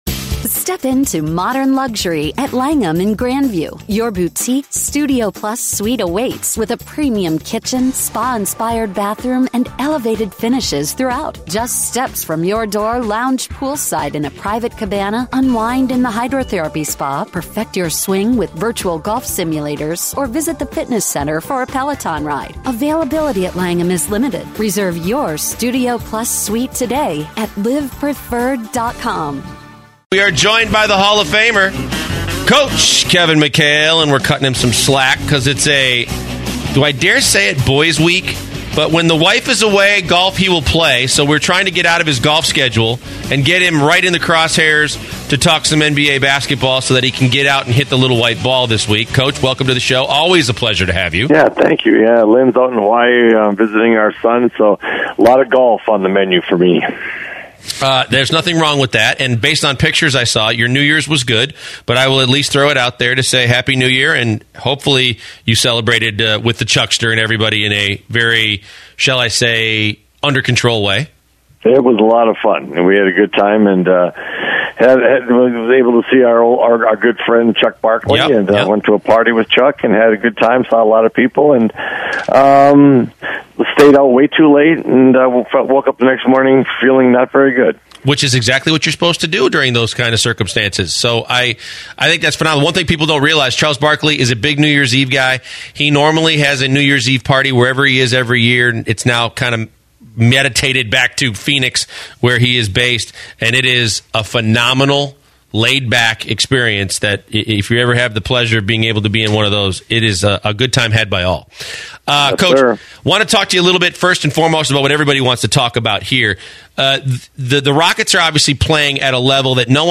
01/04/2017 Kevin McHale interview